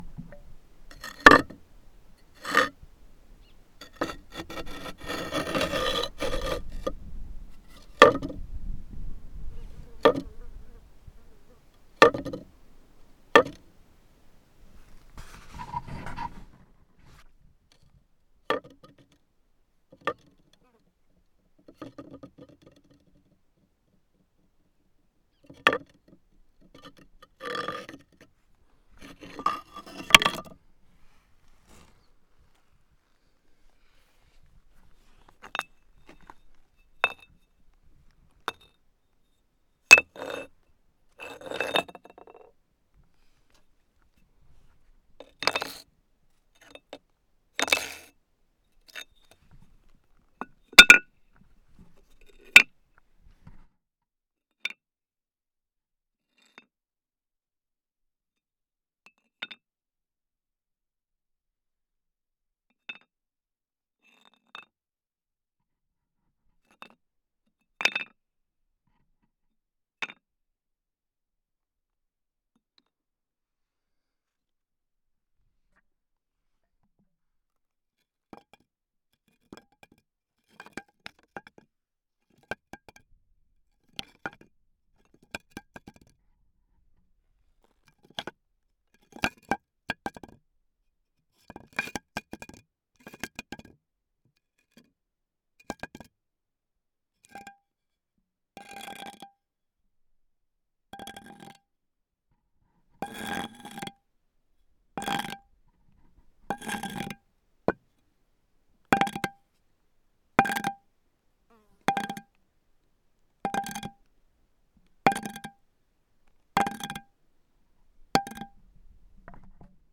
field-recording percussion scratch stone sound effect free sound royalty free Sound Effects